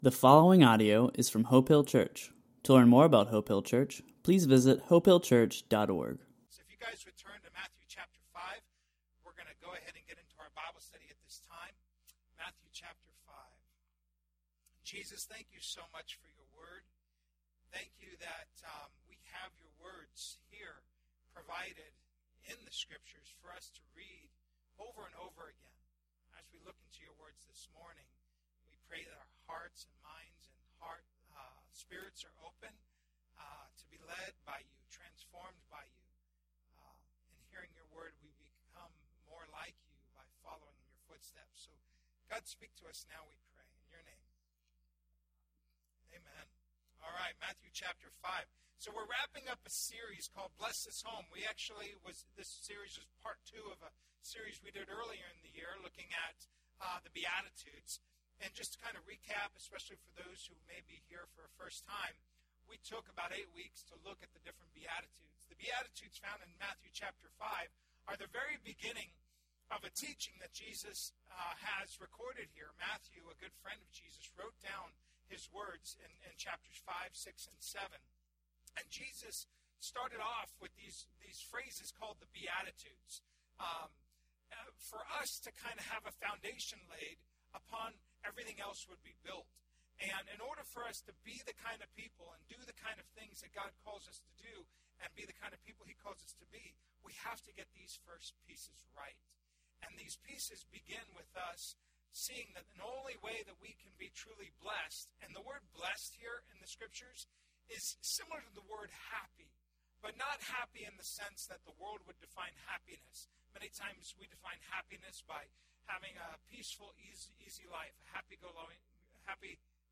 A message from the series "The Bible."